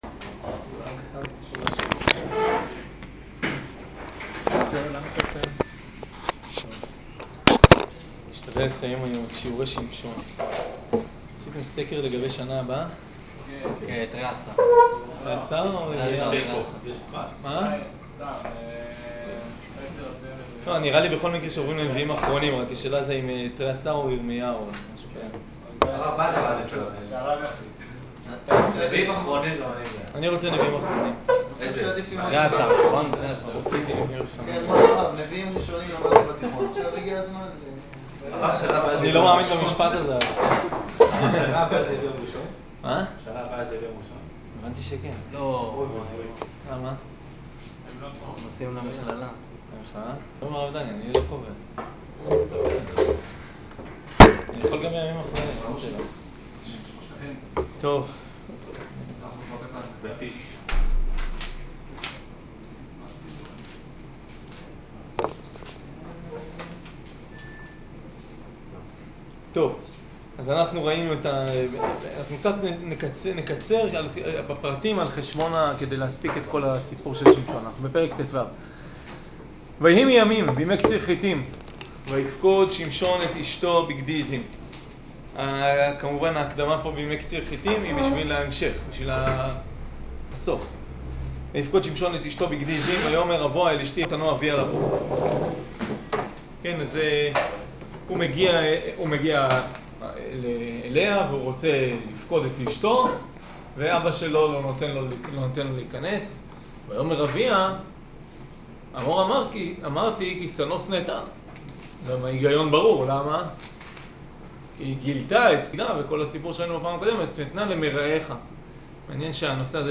שיעור שמשון